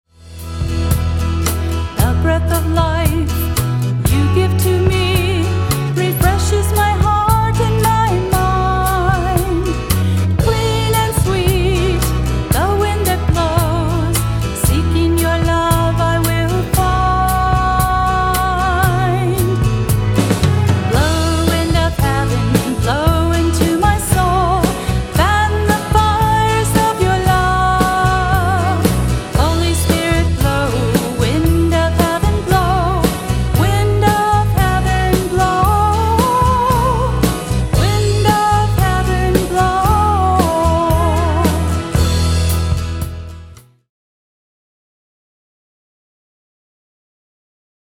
The heart of my music ministry is praise and worship!